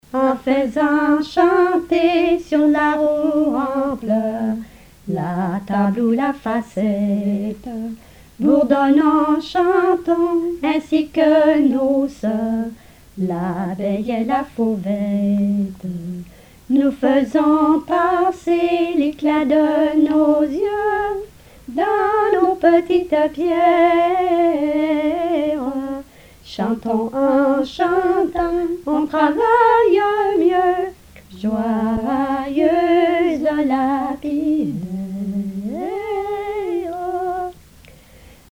Genre strophique
chansons et témoignages parlés
Pièce musicale inédite